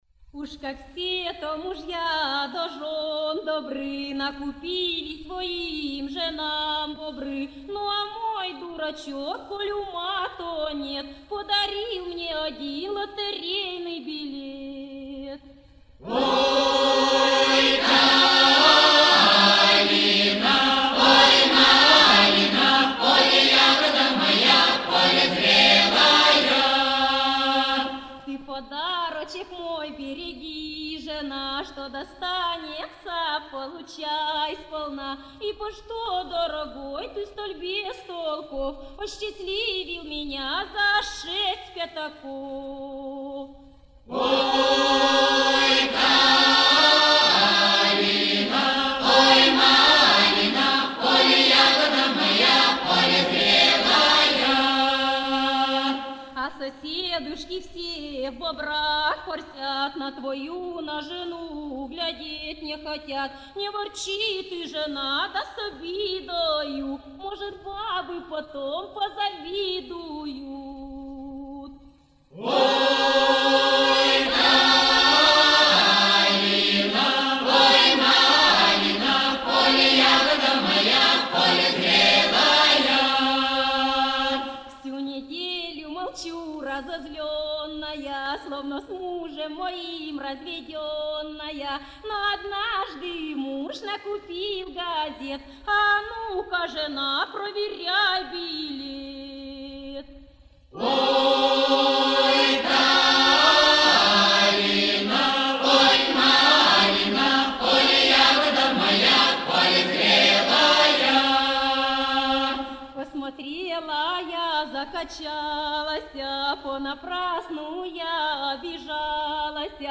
Шуточная песня.
(а’капелла)